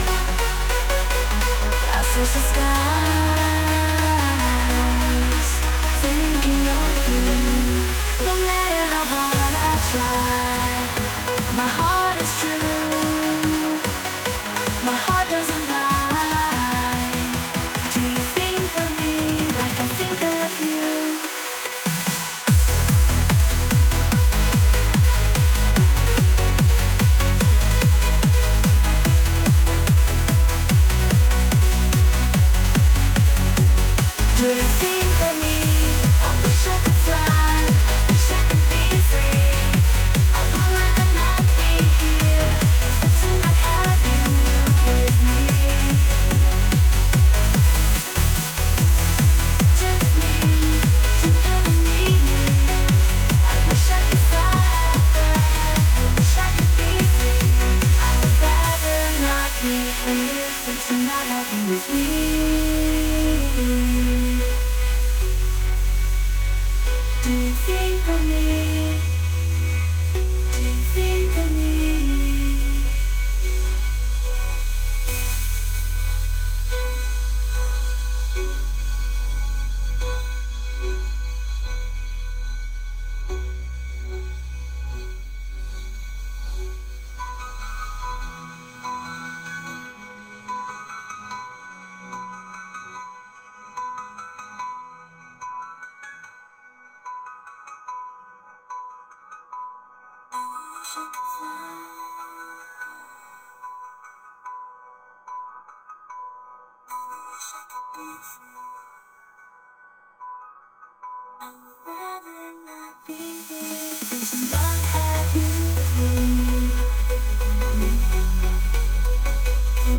Songs I made with AI
Lyrics are all mine everything else is AI.